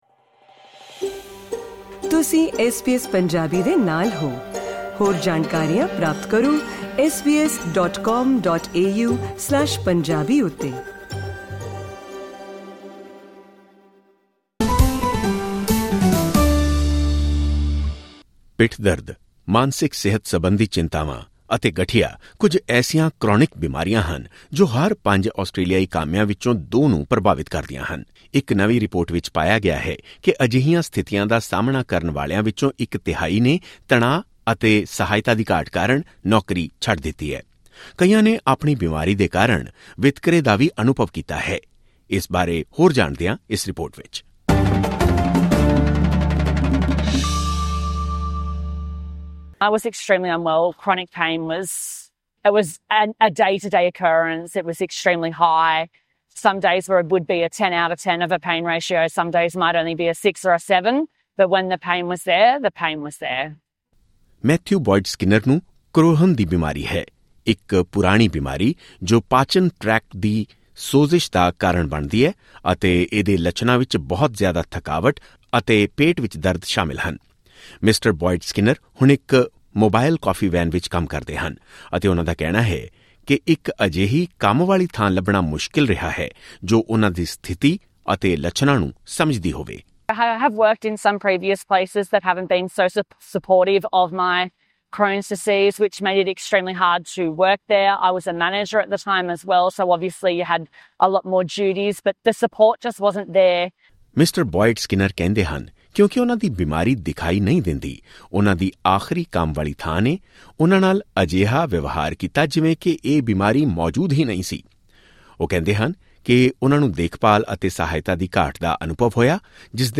ਇਸੇ ਨਾਲ ਸਬੰਧਿਤ ਇਕ ਰਿਪੋਰਟ ਇਸ ਪੌਡਕਾਸਟ ਰਾਹੀਂ ਸੁਣੀ ਜਾ ਸਕਦੀ ਹੈ।